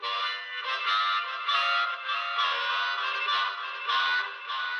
Tag: 创建 TECHNO 怪异